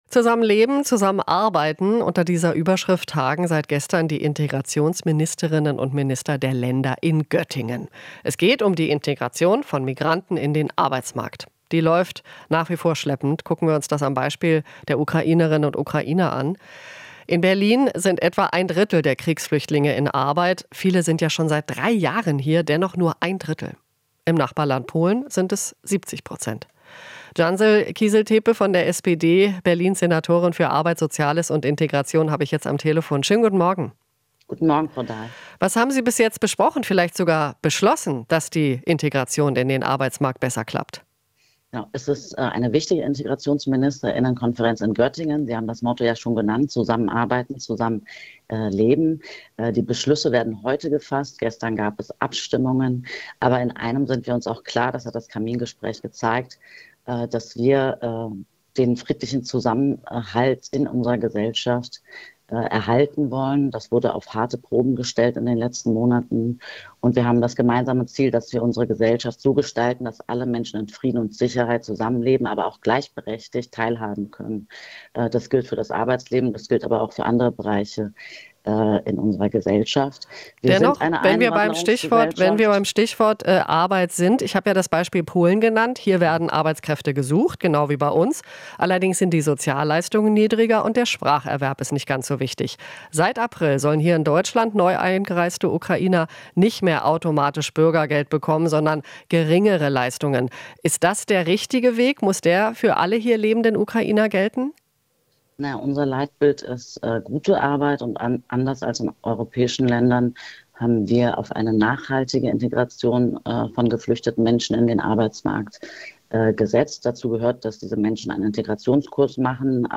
Interview - Kiziltepe (SPD): Brauchen Geld für Arbeitsmarkt-Integration